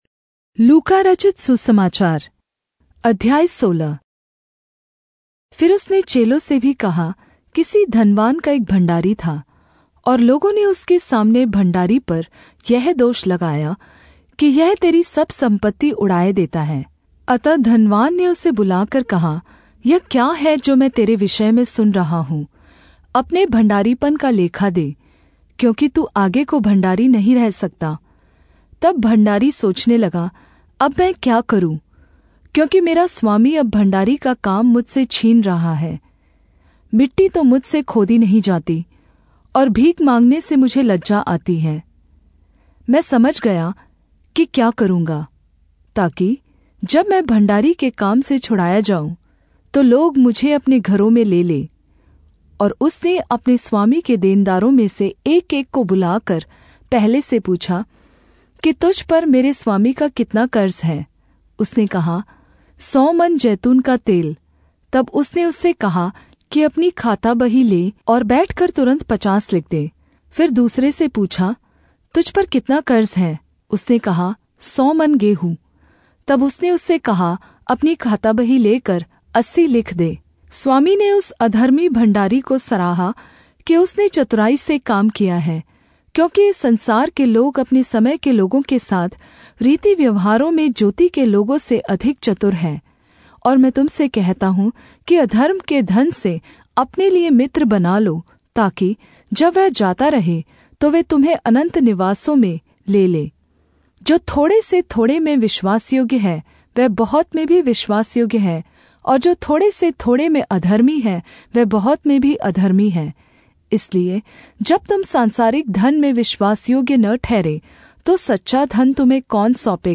Hindi Audio Bible - Luke 19 in Irvhi bible version